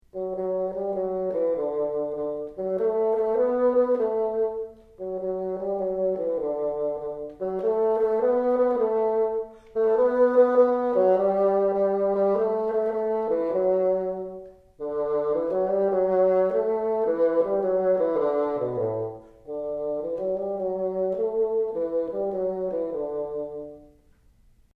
Unit 30 | Music and the Bassoon
Listen to the sound clip of America (“My Country ‘tis of Thee”) and learn to play it by ear in the key of Ab Major, starting on Ab1.